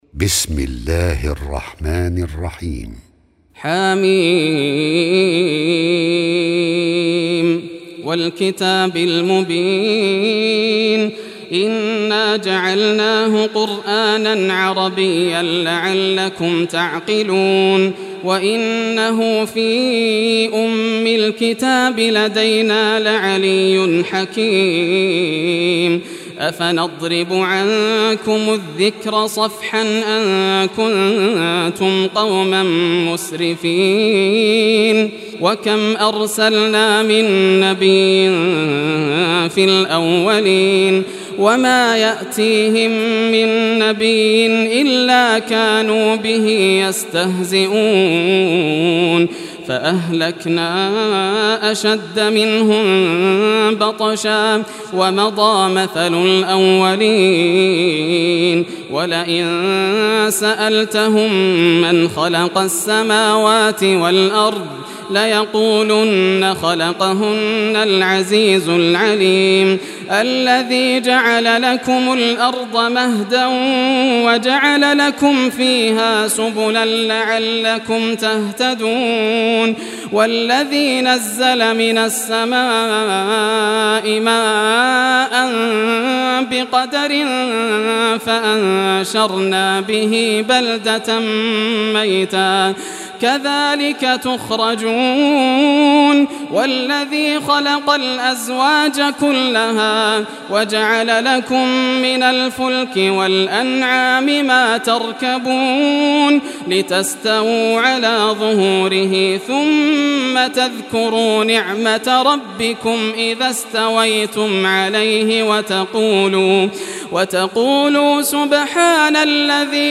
Surah Az-Zukhruf Recitation by Yasser al Dosari
Surah Az-Zukhruf, listen or play online mp3 tilawat / recitation in Arabic in the beautiful voice of Sheikh Yasser al Dosari.